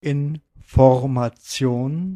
Ääntäminen
IPA: /ɪnfoʁmaˈtsi̯oːn/